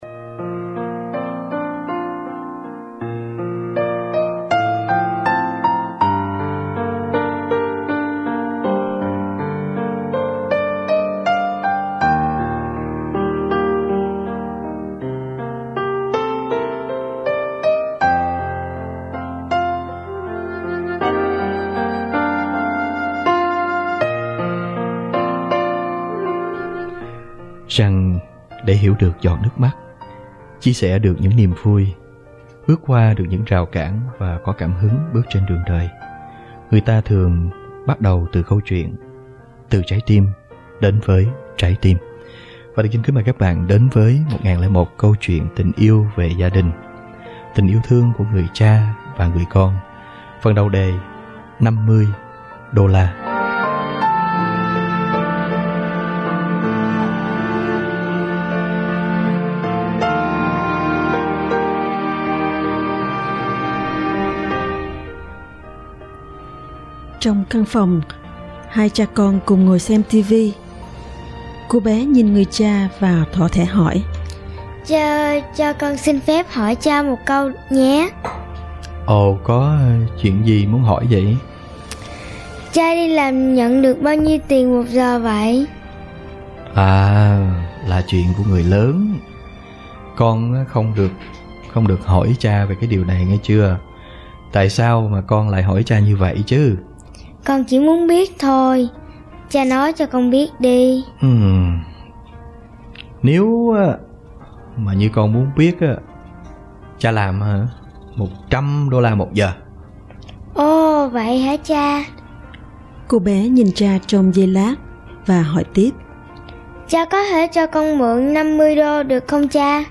loạt bài giảng